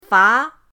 fa2.mp3